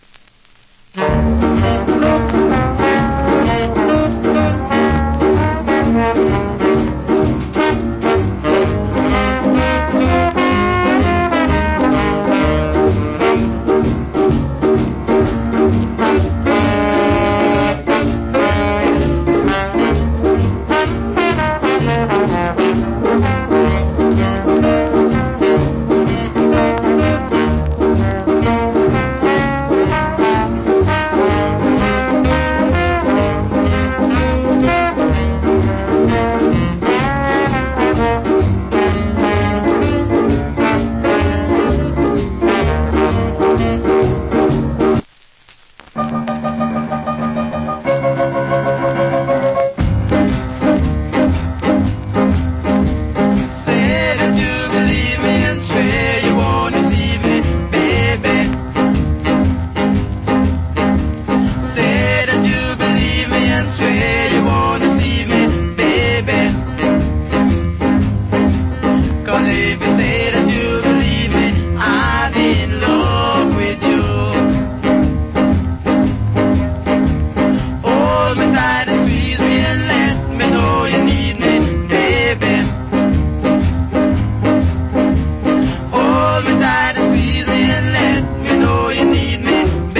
Nice Shuffle Inst.